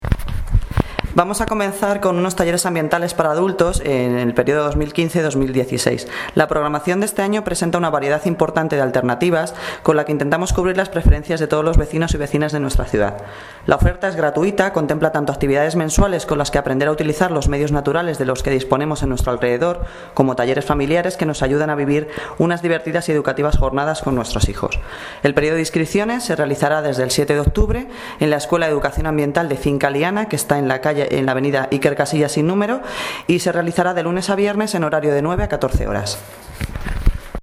Audio - Noelia Posse (Concejala de Medio Ambiente y Servicios Generales) Sobre Talleres Medio Ambientales